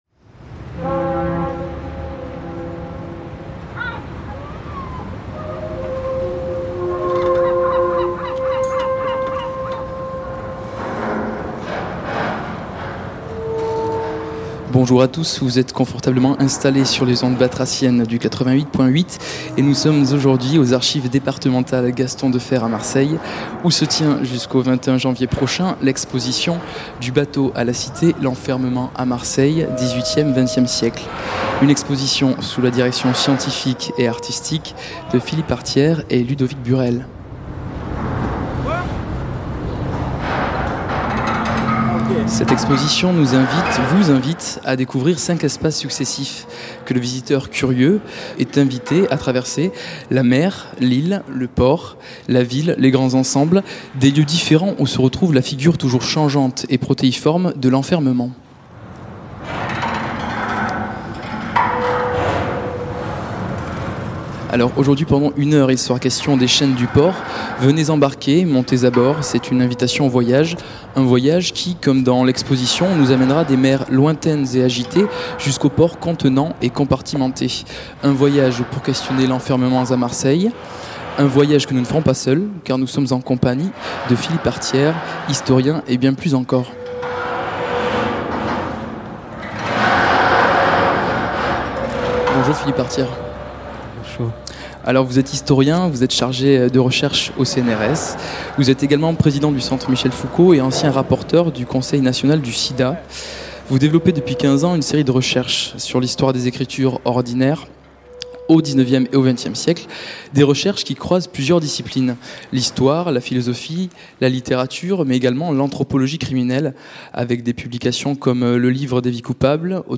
enregistré depuis les archives départementales de Marseille le 20 octobre 2011.
Entretien